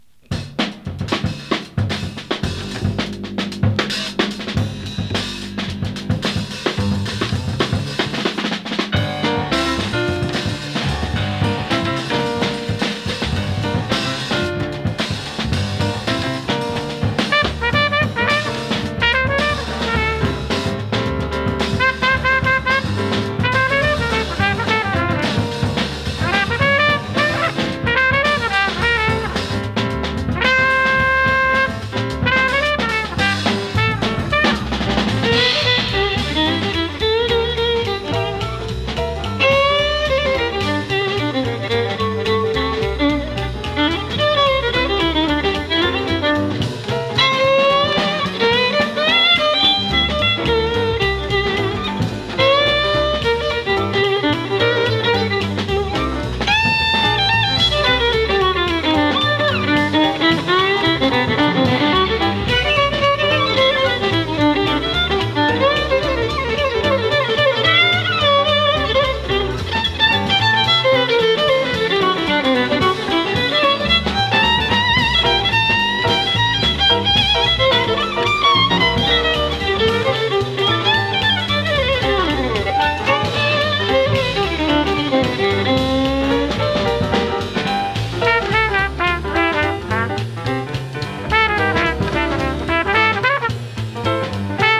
アコースティック スウィング